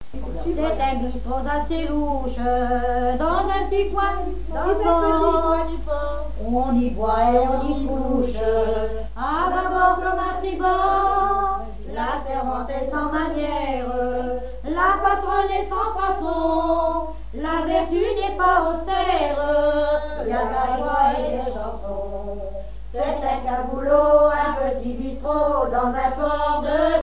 Genre strophique
répertoire des femmes de conserveries
Pièce musicale inédite